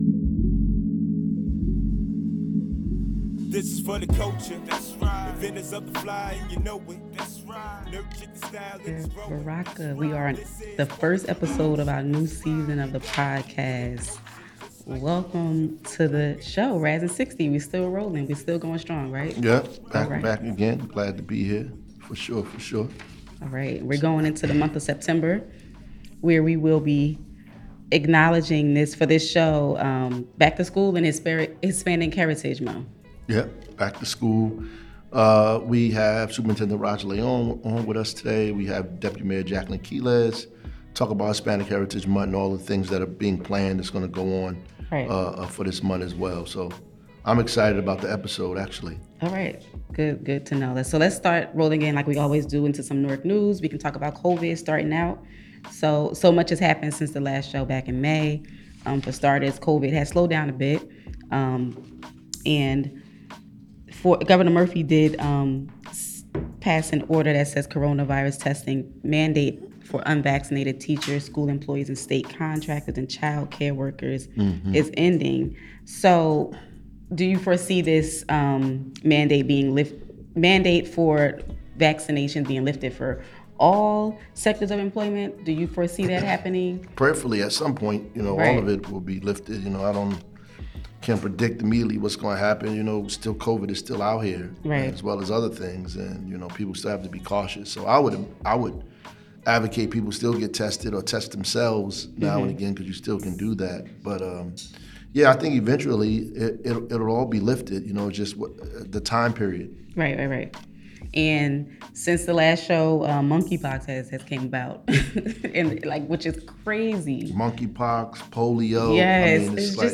On the second half of the show we transition into our conversation with Deputy Mayor of Community Engagement, Jacqueline Quiles.